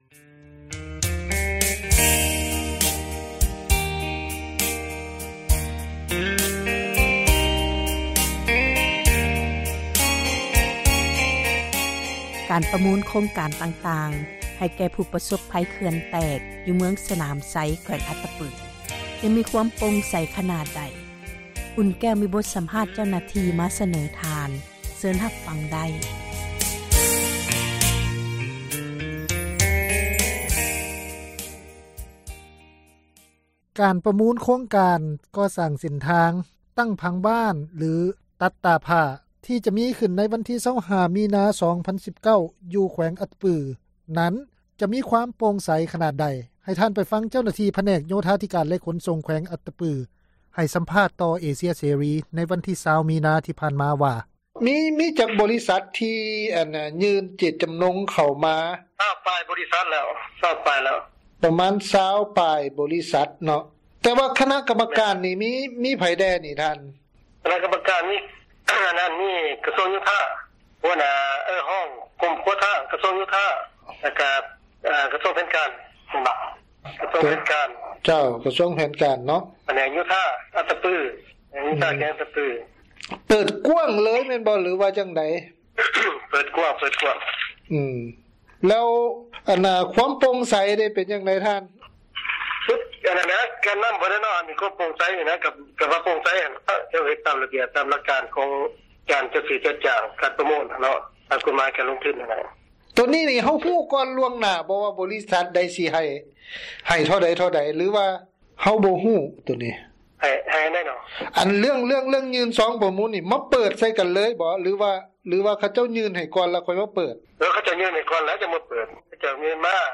ການປະມູນ ໂຄງການ ກໍ່ສ້າງເສັ້ນທາງ, ຕັ້ງຜັງເມືອງ ຫລື ຕັດຕາຜ້າ, ທີ່ຈະມີຂຶ້ນ ໃນ ວັນທີ 25 ມີ 2019 ຢູ່ ແຂວງອັດຕະປື ນັ້ນ, ຈະມີຄວາມໂປ່ງໃສ ຂນາດໃດ, ເຊີນທ່ານ ຮັບຟັງຈາກ ເຈົ້າໜ້າທີ່ ຜແນກ ໂຍທາທິການ ແລະ ຂົນສົ່ງ ແຂວງອັດຕະປື ທີ່ ໃຫ້ສຳພາດ ຕໍ່ ວິທຍຸເອເຊັຽເສຣີ ໃນວັນທີ 20 ມີນາ 2019 ນີ້.